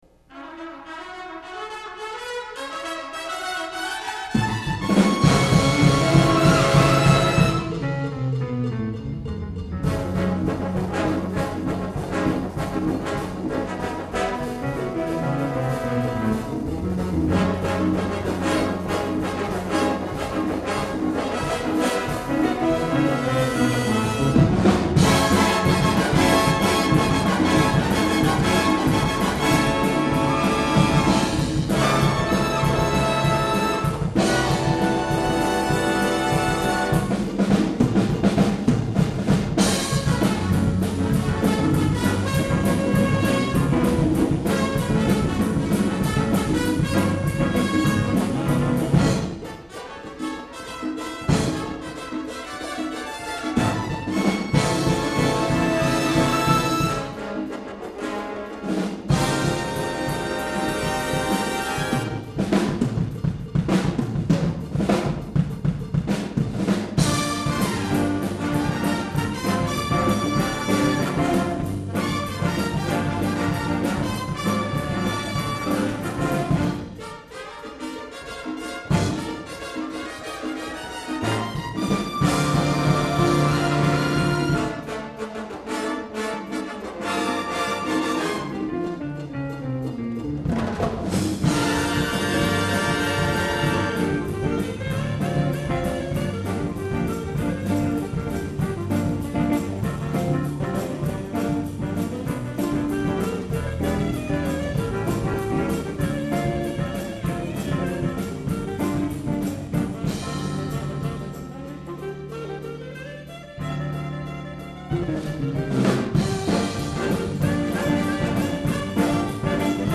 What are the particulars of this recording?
1989 Winter Concert